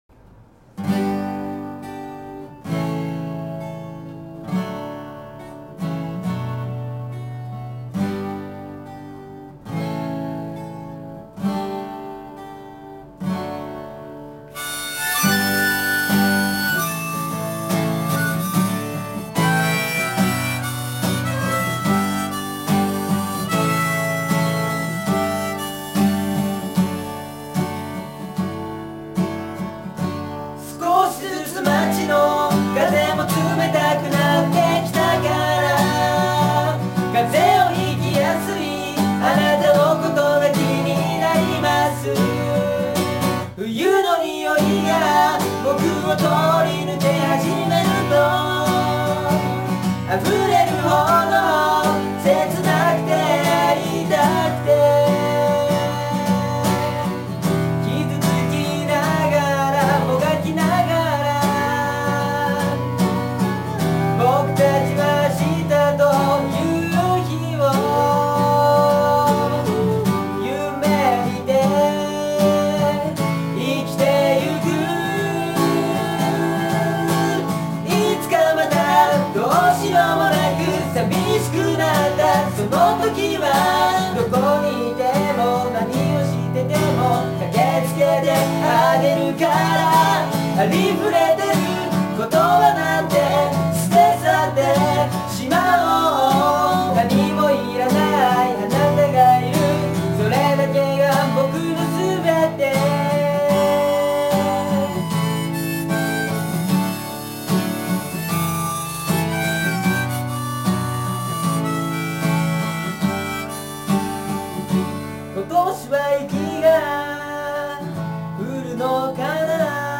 聞き苦しい点が多々ありますが、練習段階を一発録りしたものなので勘弁してくださいm(_ _)m
完成度は低いけど、一応アップします...いきなり肝心の出だしでハープ失敗してます(^^;)。
最後も油断しててヘロヘロになっちゃいました(>_<)。もう無理矢理終わらせてる感じだね（笑）。